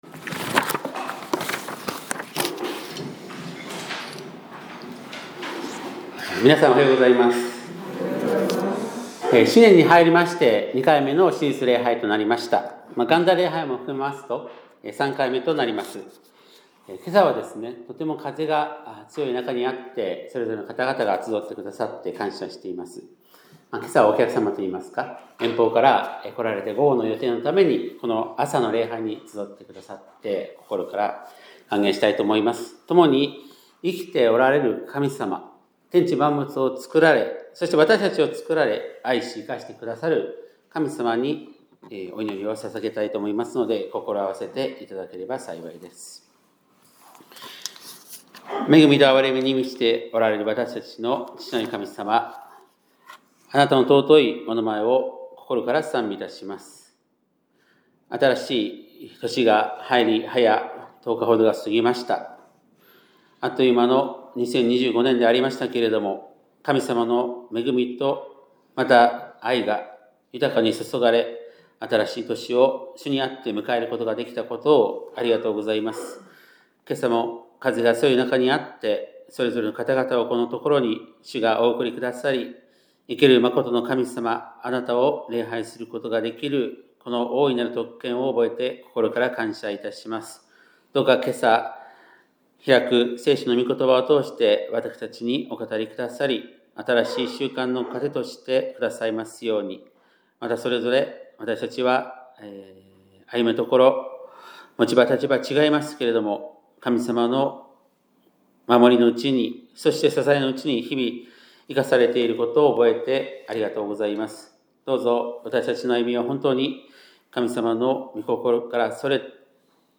2026年1月11日（日）礼拝メッセージ